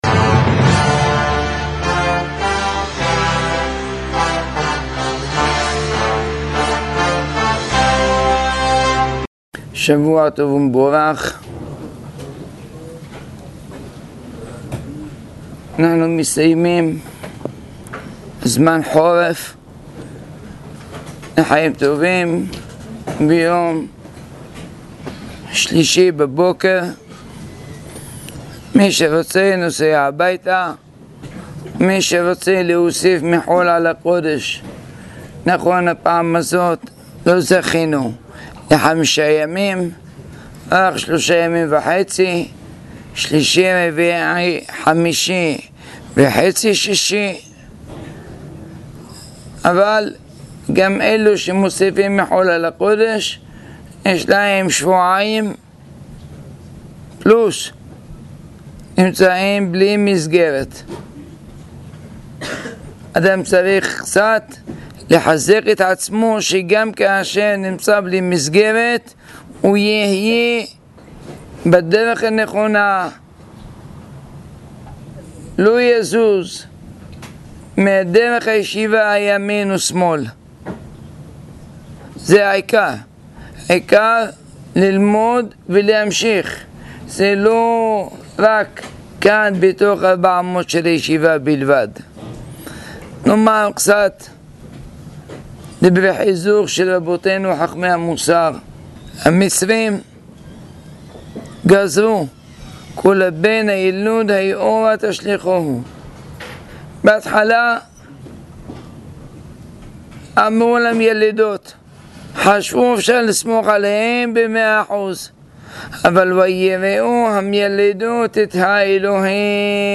במסילות הפרשה | שיחה מחזקת ומעניינת מאוד.